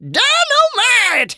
tnt_guy_kill_02.wav